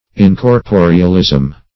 Search Result for " incorporealism" : The Collaborative International Dictionary of English v.0.48: Incorporealism \In`cor*po"re*al*ism\, n. Existence without a body or material form; immateriality.
incorporealism.mp3